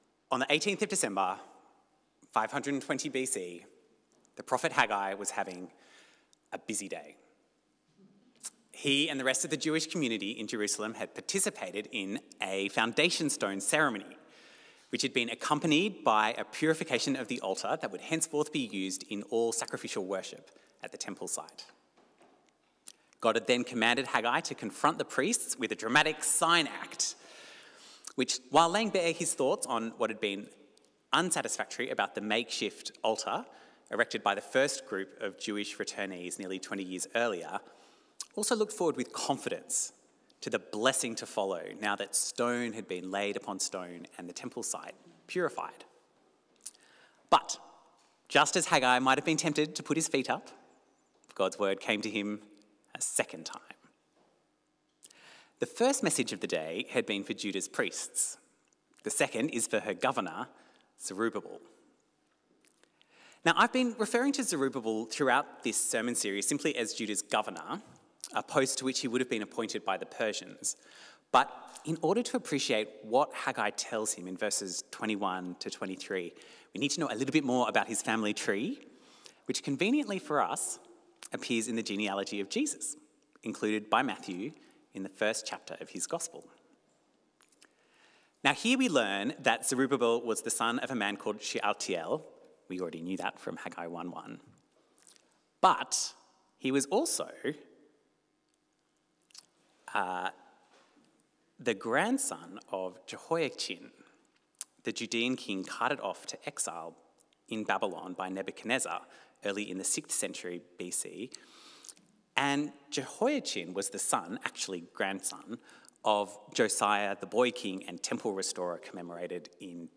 A sermon on Haggai 2:20-23